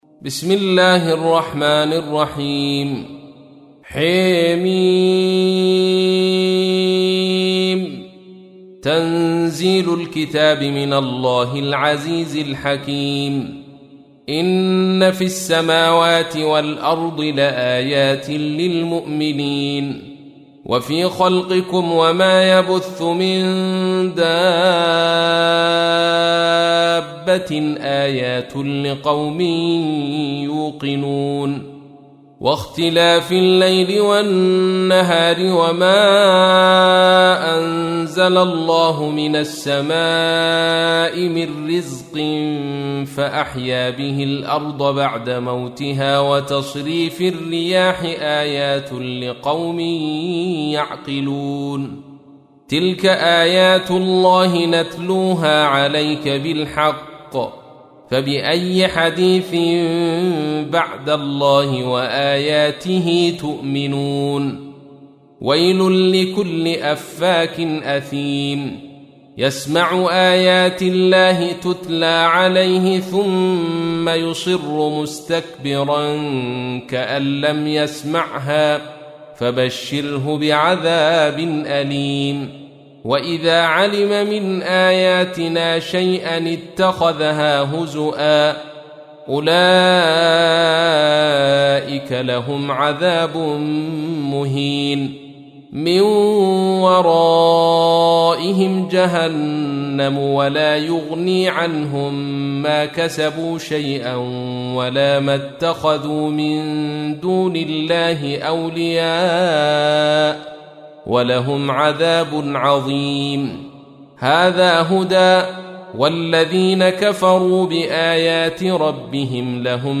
تحميل : 45. سورة الجاثية / القارئ عبد الرشيد صوفي / القرآن الكريم / موقع يا حسين